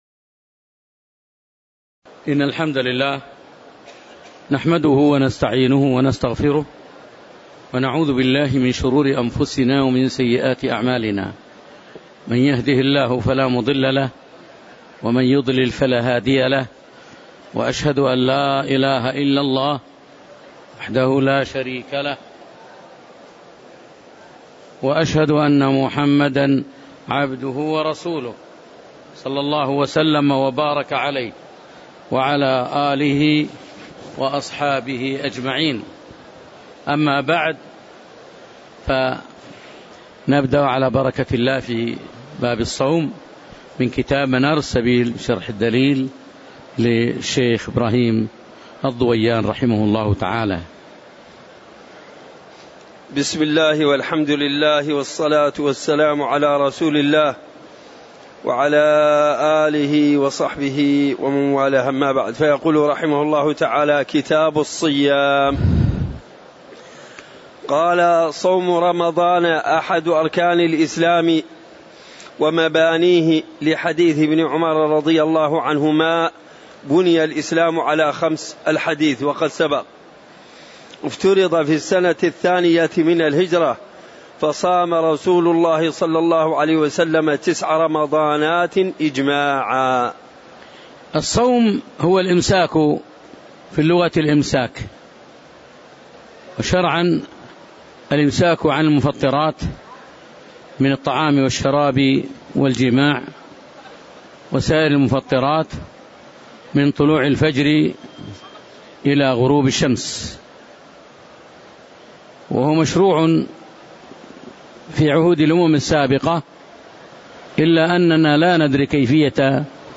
تاريخ النشر ١ رمضان ١٤٣٨ هـ المكان: المسجد النبوي الشيخ